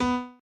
b_pianochord_v100l8o4b.ogg